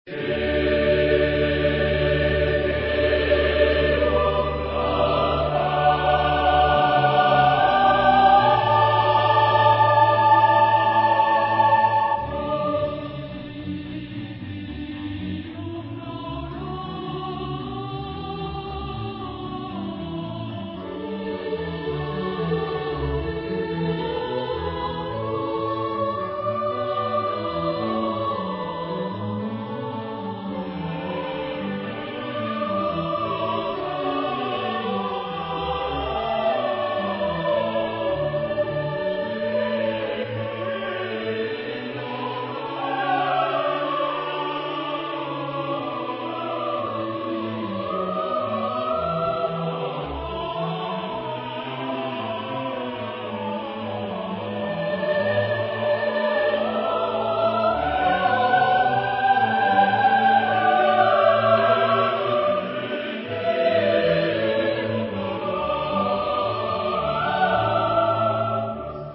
SATB + SATB OR SSAATTBB (8 voices double choir OR mixed) ; Full score.
Romantic. Motet.
sung by Kammerchor Stuttgart conducted by Frieder Bernius